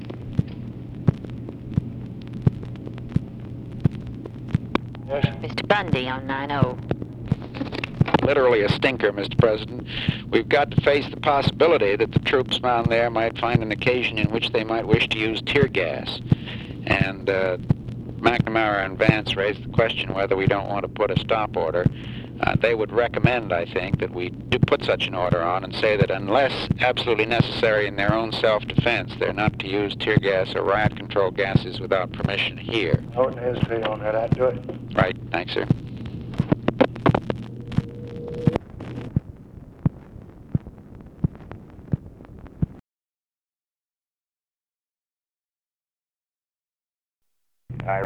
Conversation with MCGEORGE BUNDY, May 1, 1965
Secret White House Tapes